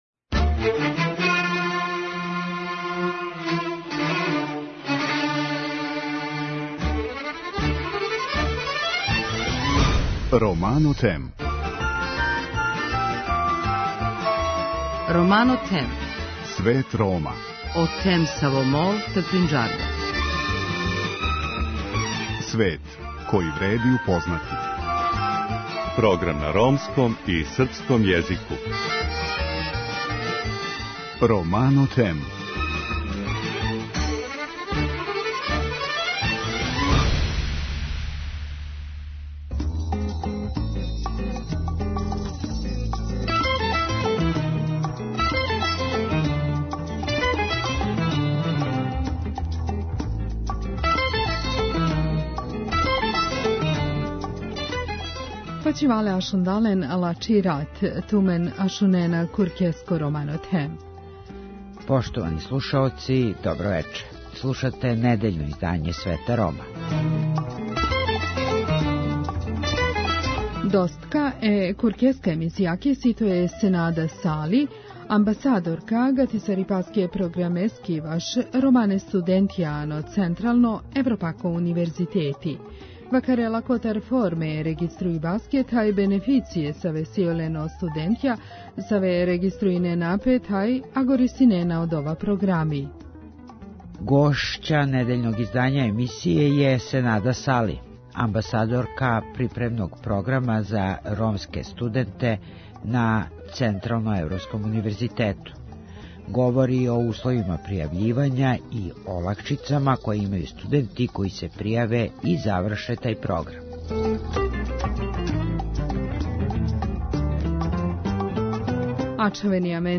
Говори о условима пријављивања и олакшицама које имају студенти који се пријављују за тај програм. Чућемо и искуства некадашњих полазника.